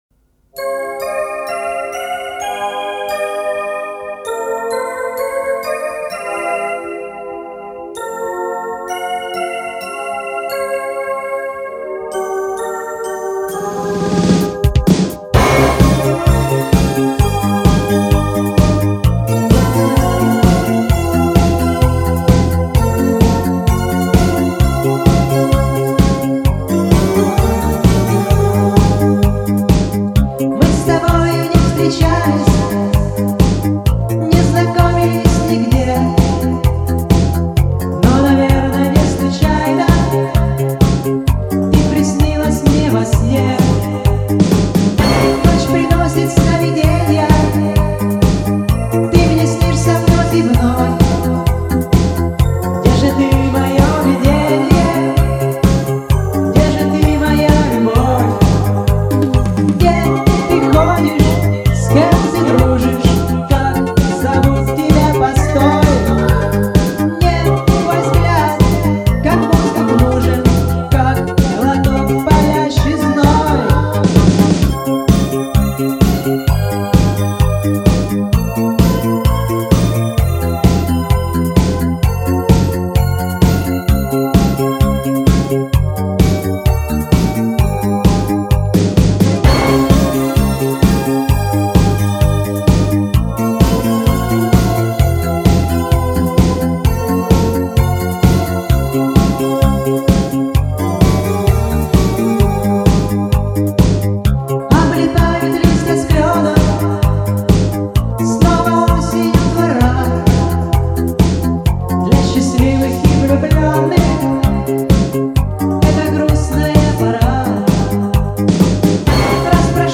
вот 2 варианта с усилением низких.